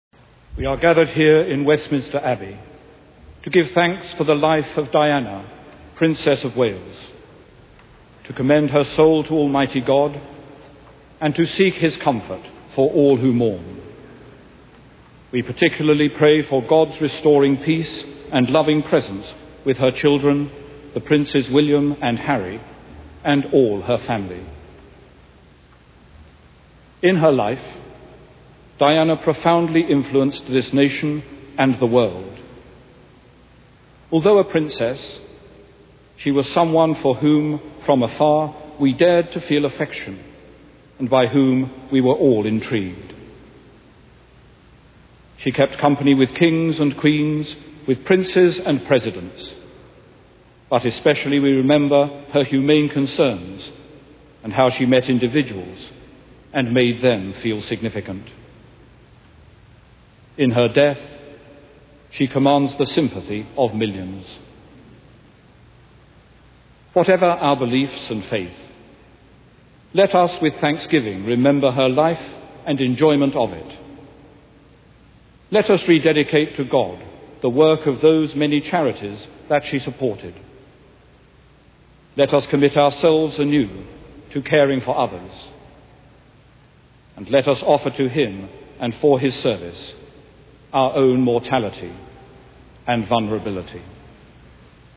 The Funeral of Diana, Princess of Wales
Westminster Abbey, September 6, 1997, 11:00 a.m. BST
hear The Bidding, The Very Reverend Dr. Wesley Carr, Dean of Westminster